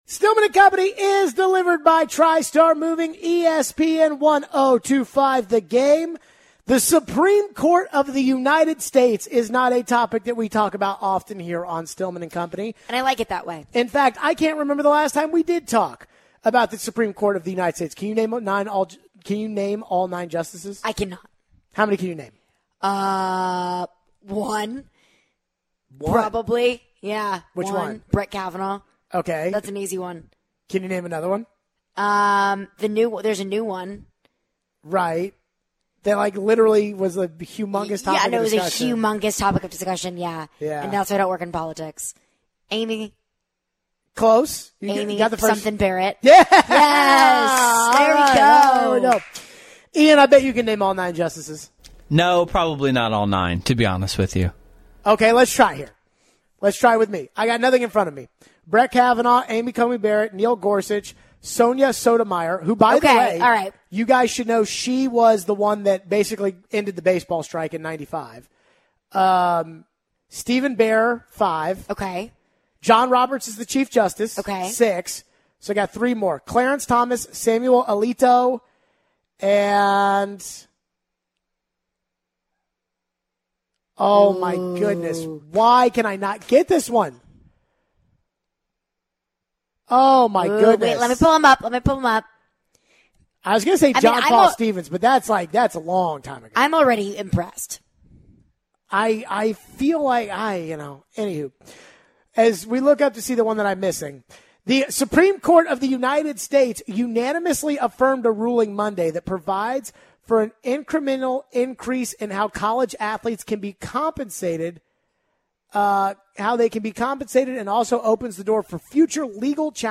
We take your phones. We wrap up the show with a little betting talk on 'CaroLINES'.